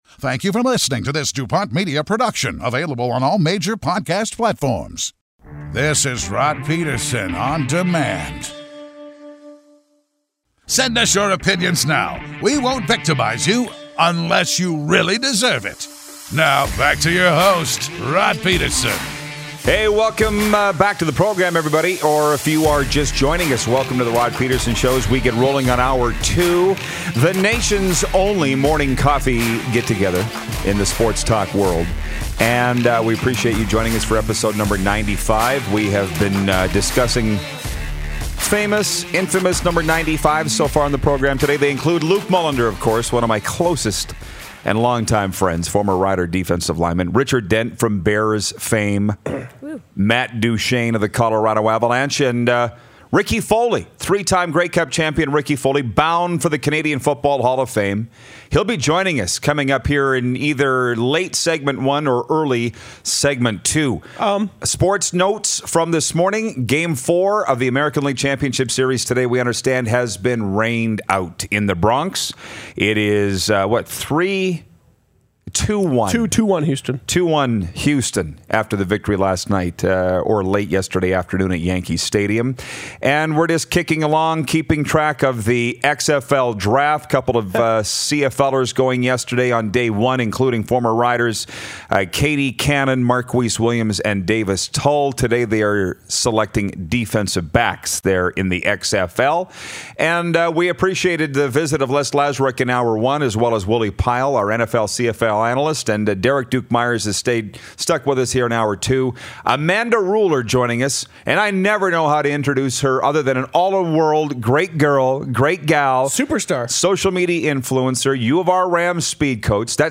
Lots to talk about and discuss today! 3-time Grey Cup Champion, Ricky Foley calls in!